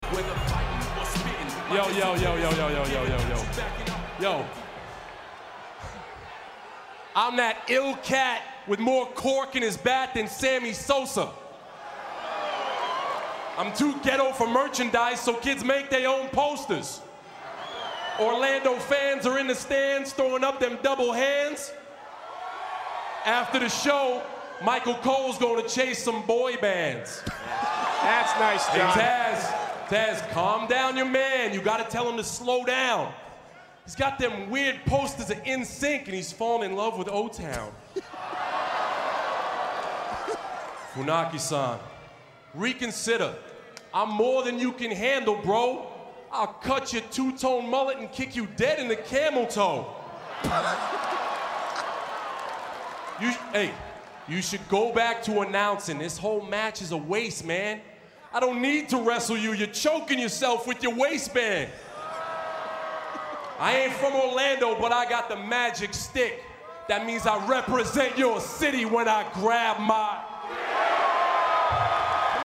Before the match can start however, we get some rapping from John, one line of which talks about